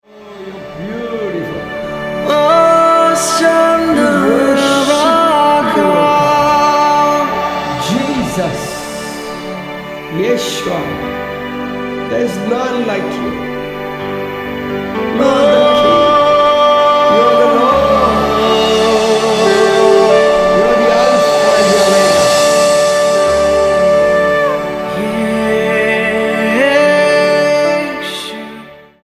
STYLE: World
4. Spontaneous Worship